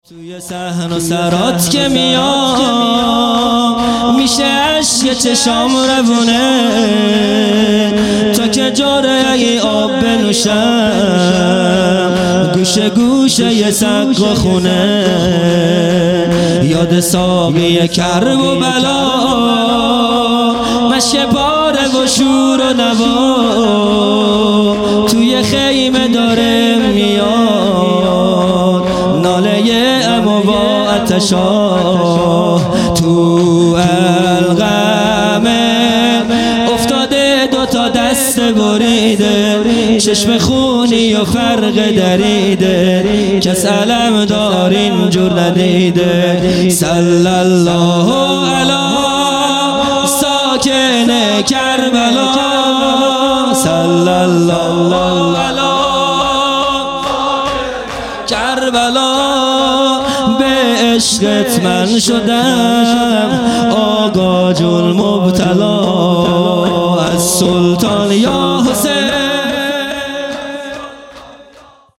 خیمه گاه - هیئت بچه های فاطمه (س) - شور | توی صحن و سرات که میام
عزاداری دهه آخر صفر المظفر (شب سوم)